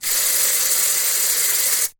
spray corto
spray_short.mp3